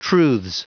Prononciation du mot truths en anglais (fichier audio)
Prononciation du mot : truths